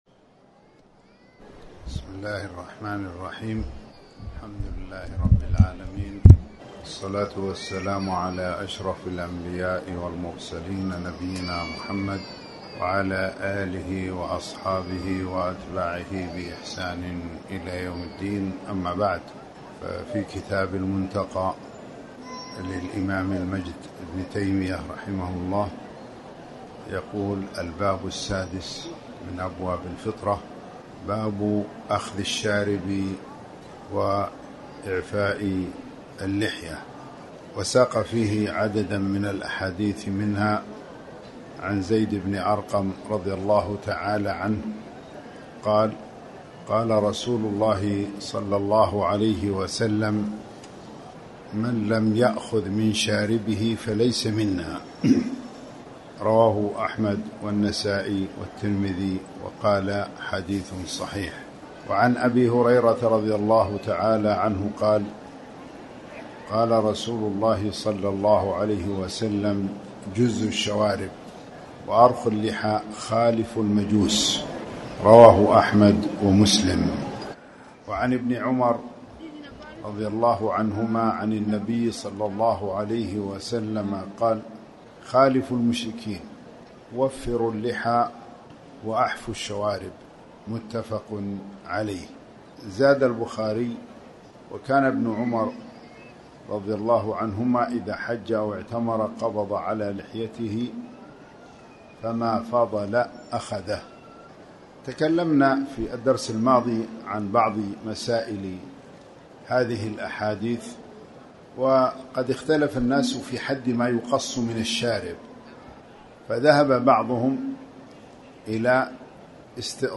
تاريخ النشر ٤ صفر ١٤٣٩ هـ المكان: المسجد الحرام الشيخ